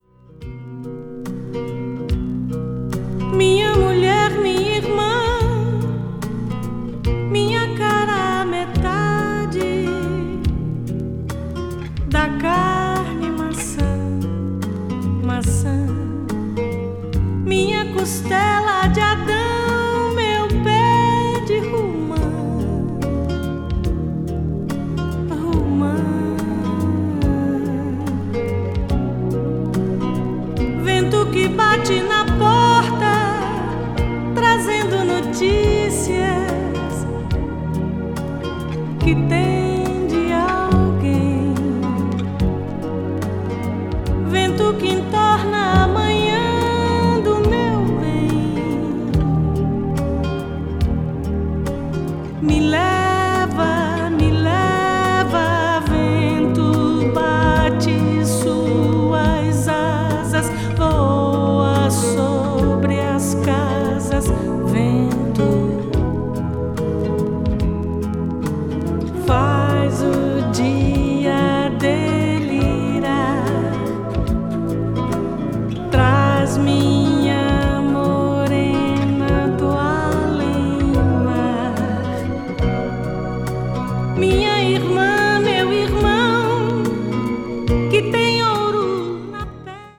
a.o.r.   brazil   mellow groove   mpb   pop   world music